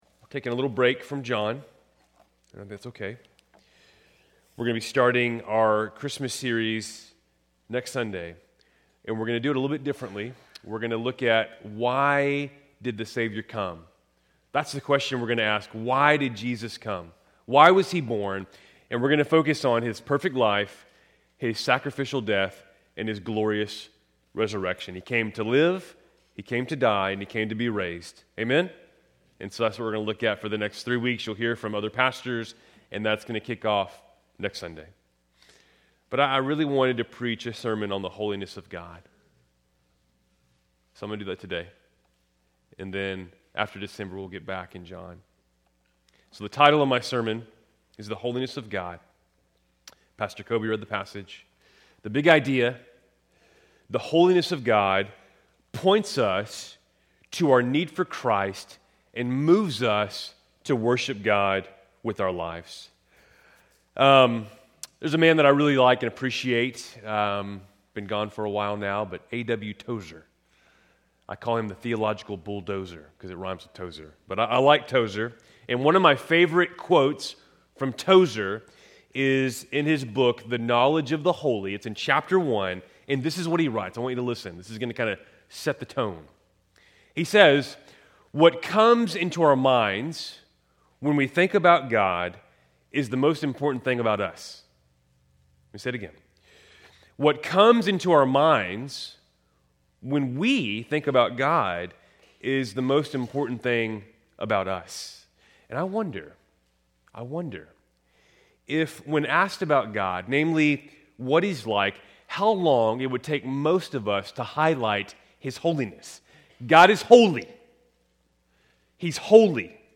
Keltys Worship Service, November 30, 2025